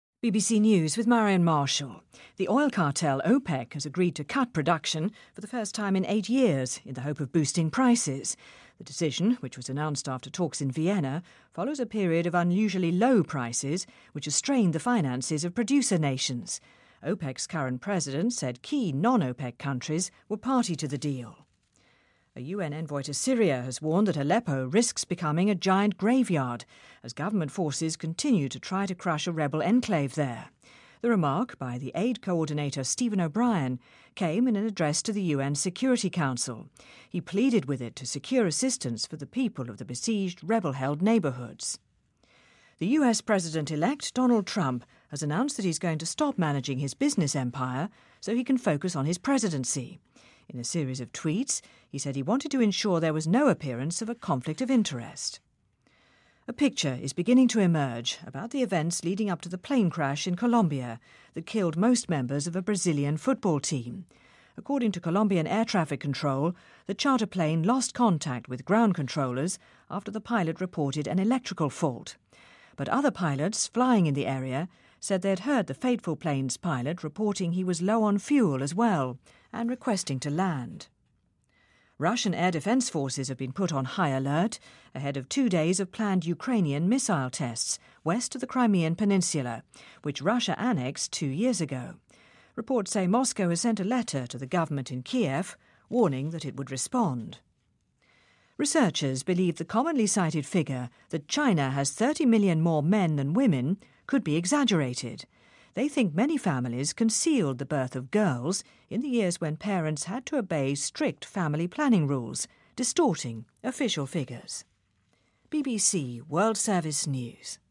BBC news,中国男性比女性多3千万的数据可能是夸大其词